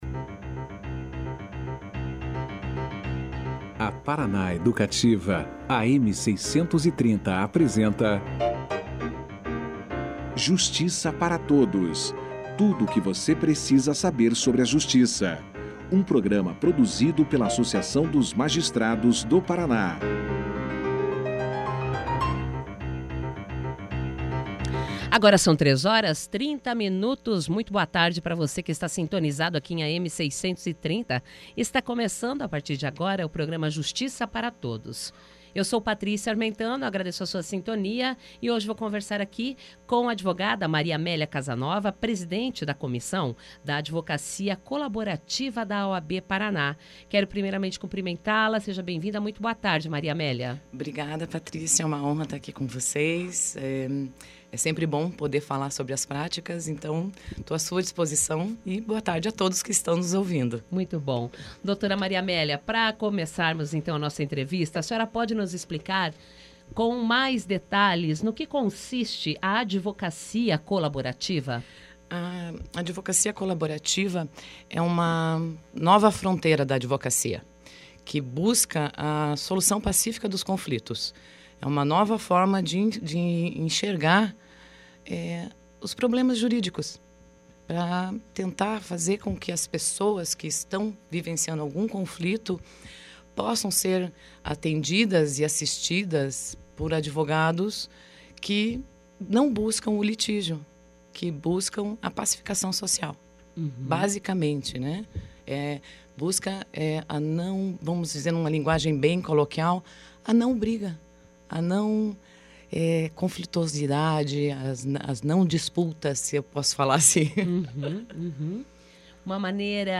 Ela falou que com as práticas colaborativas, o advogado se torna um resolvedor de conflitos do cliente, não um ajuizador de processos. Confira aqui a entrevista na íntegra.